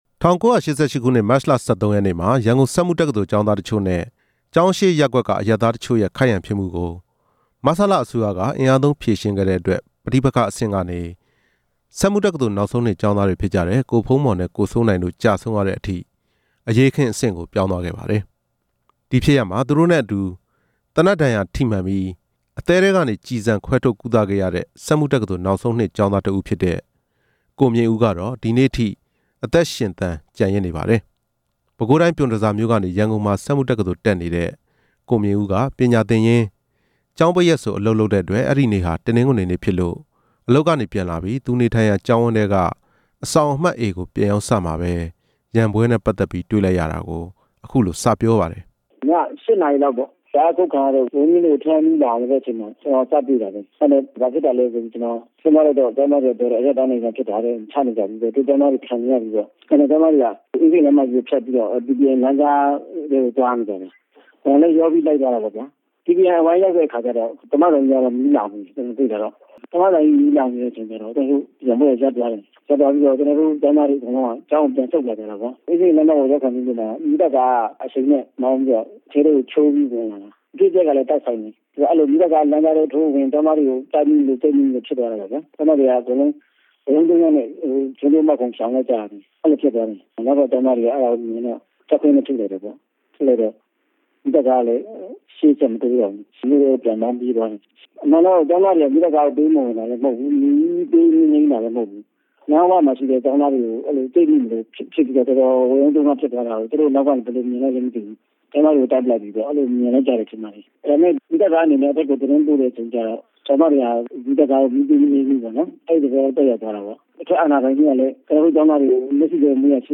၁၉၈၈ မတ် ၁၃ ရက်နေ့က ကျောင်းသားတွေ ပစ်ခတ်ခံရတဲ့အကြောင်း မေးမြန်းချက်